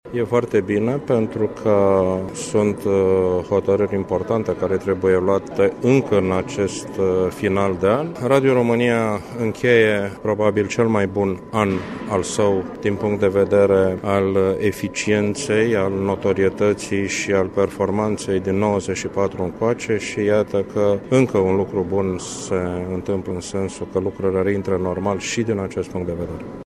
Preşedintele director general al Radiodifuziunii Române, Ovidiu Miculescu spune că în urma votului de ieri din plen, Consiliul a devenit funcţional: